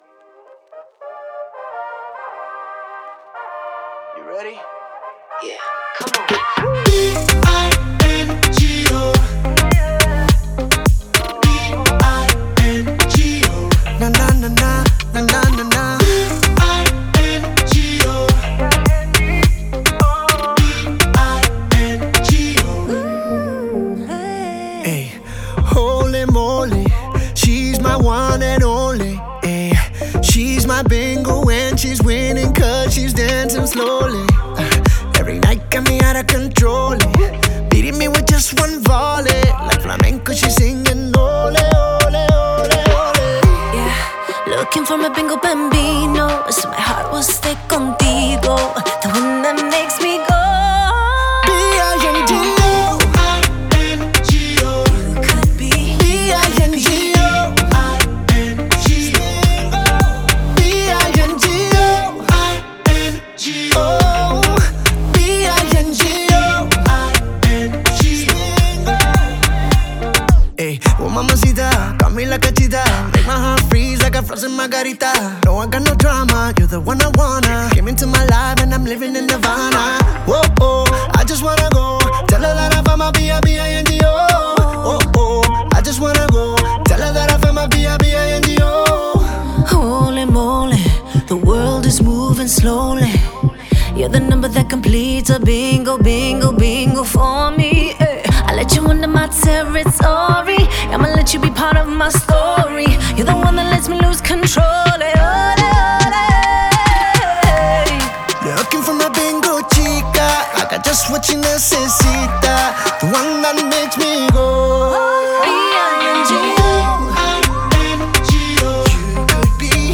зажигательная поп-танцевальная композиция